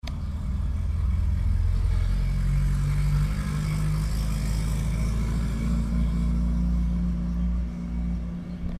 AUTO PASANDOCARRO PASANDO
Ambient sound effects
auto_pasandocarro_pasando.mp3